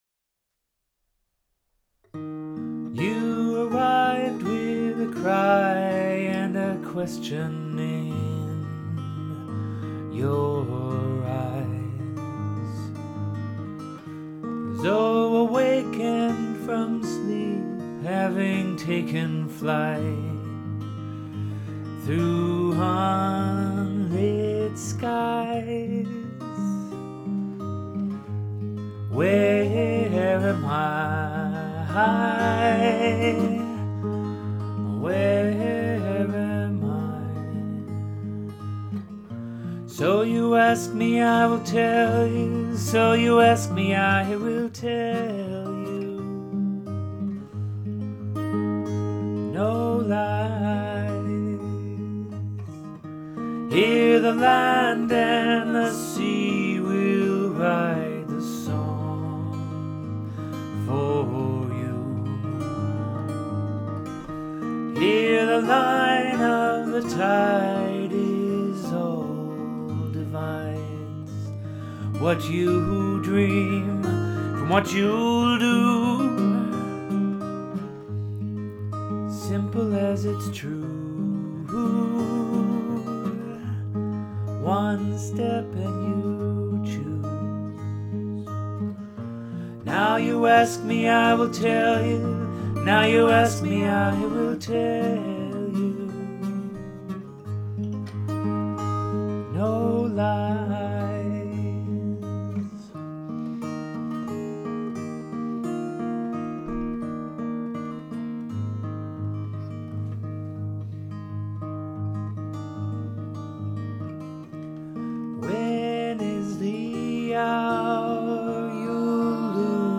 With a new, open-source recording program (“open-source” means free, communally-built — just how it should be, or could be), I decided to put both technology and voice through their paces, and spent a couple of hours figuring out the software and hardware, then a couple more repeating track after track until the song was close enough to “right”.